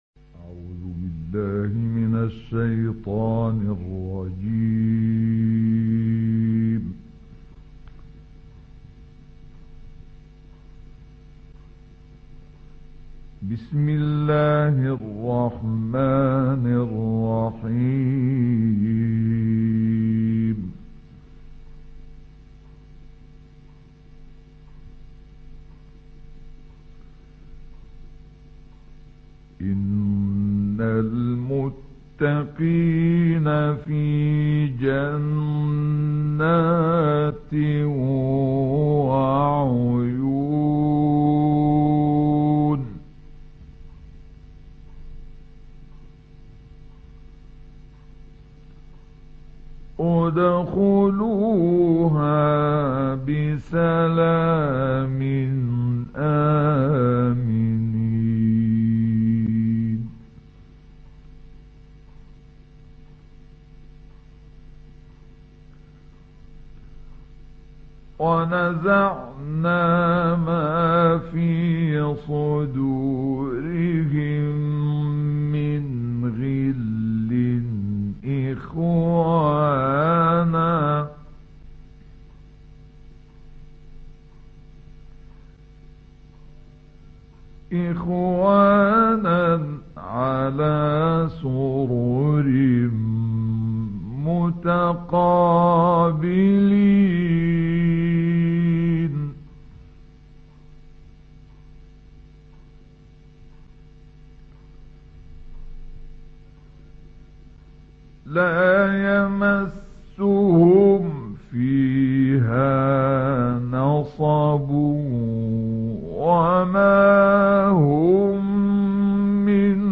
گروه شبکه اجتماعی: تلاوت آیاتی از سوره مبارکه حجر با صوت عبدالفتاح شعشاعی را می‌شنوید.
مدت زمان این تلاوت استودیویی 28 دقیقه است.